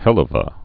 (hĕlə-və) Slang